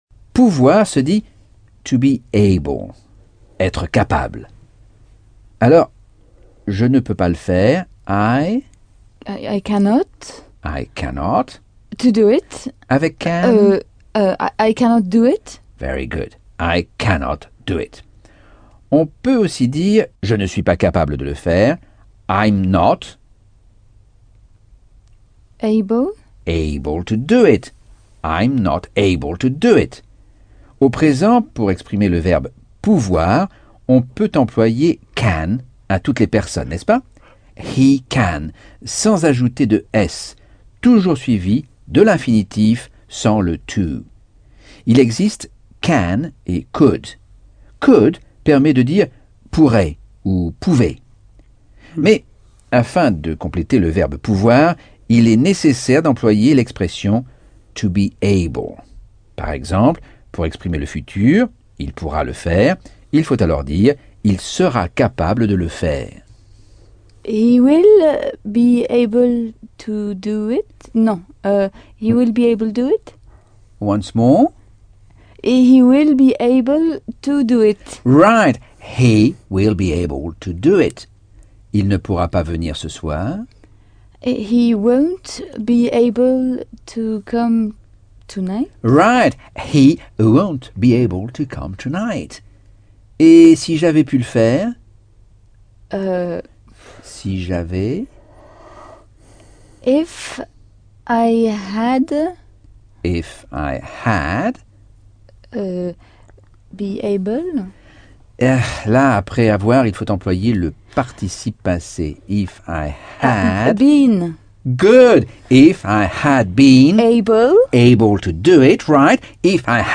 Leçon 6 - Cours audio Anglais par Michel Thomas - Chapitre 11